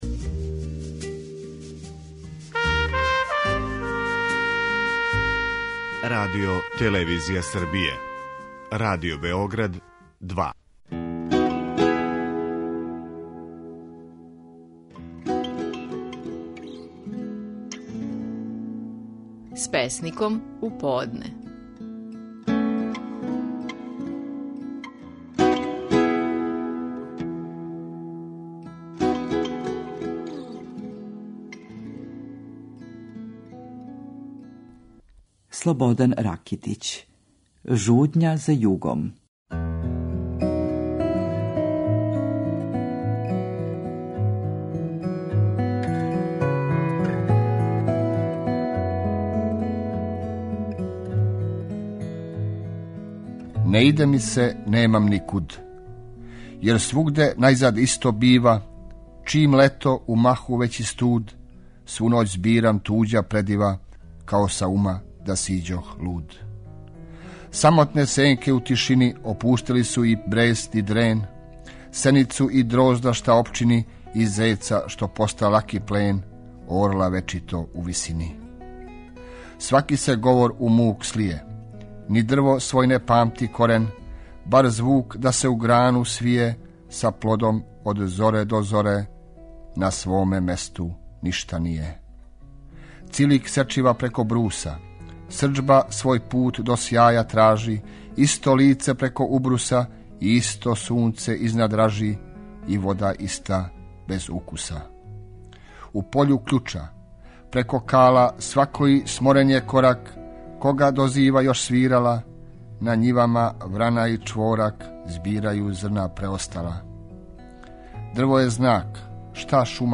Наши најпознатији песници говоре своје стихове
У данашњој емисији слушамо Слободана Ракитића и његову песму „Жудња за југом".